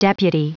Prononciation du mot deputy en anglais (fichier audio)
Prononciation du mot : deputy